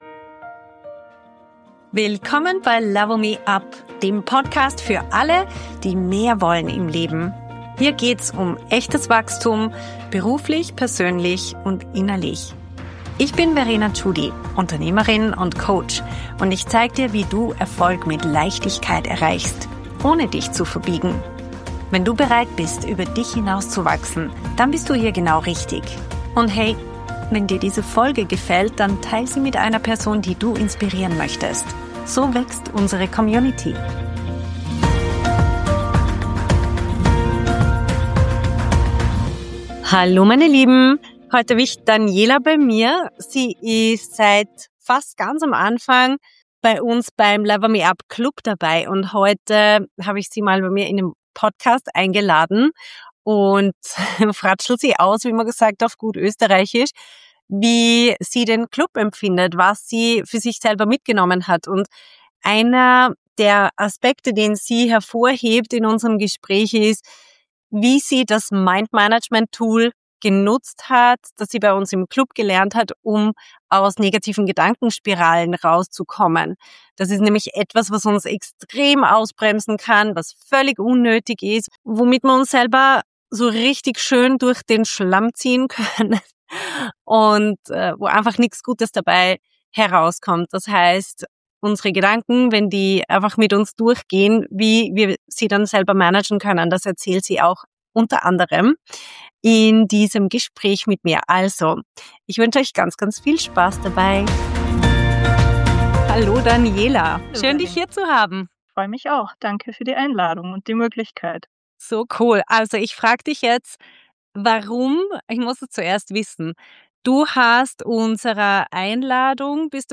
Adé negative Gedankenspiralen – Interiew